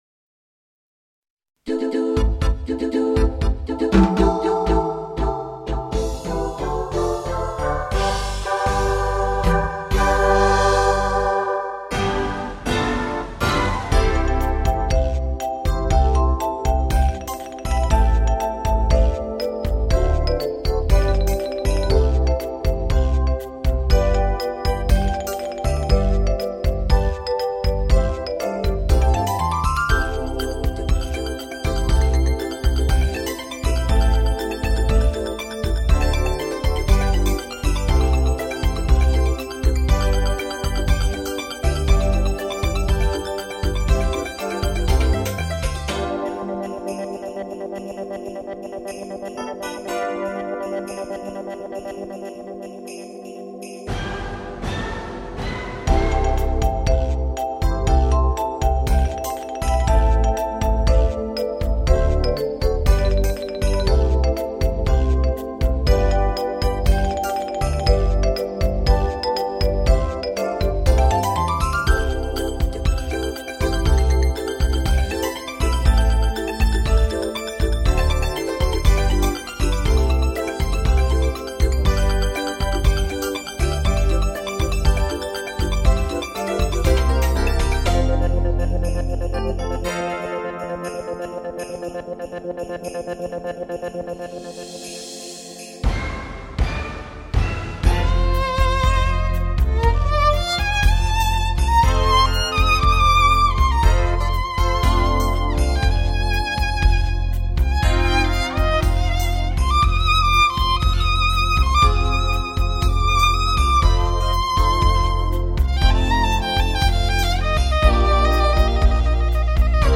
小提琴独奏
中提琴领奏
洞箫/竹笛独奏
二胡/中胡独奏
琵琶独奏
弦乐伴奏
男声伴唱
女声独唱/领唱/伴唱